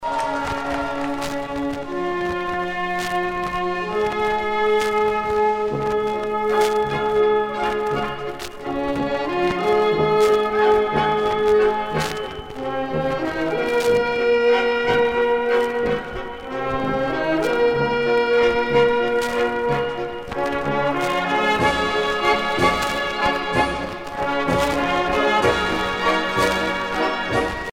valse viennoise